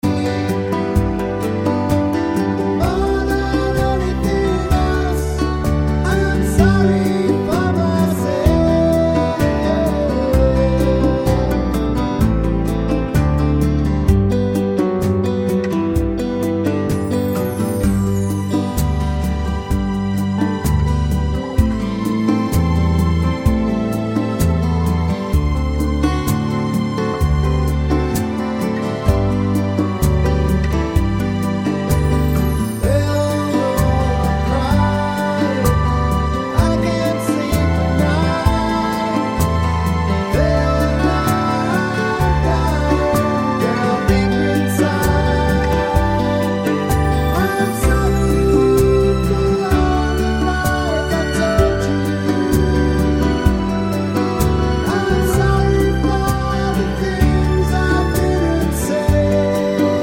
no Backing Vocals Country (Male) 3:31 Buy £1.50